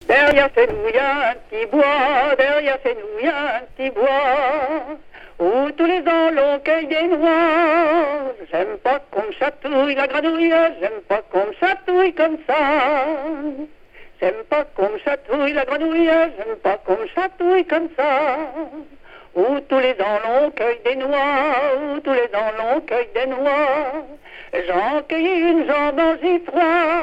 Localisation Île-d'Yeu (L')
Genre laisse
chansons traditionnelles